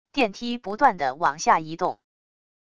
电梯不断的往下移动wav音频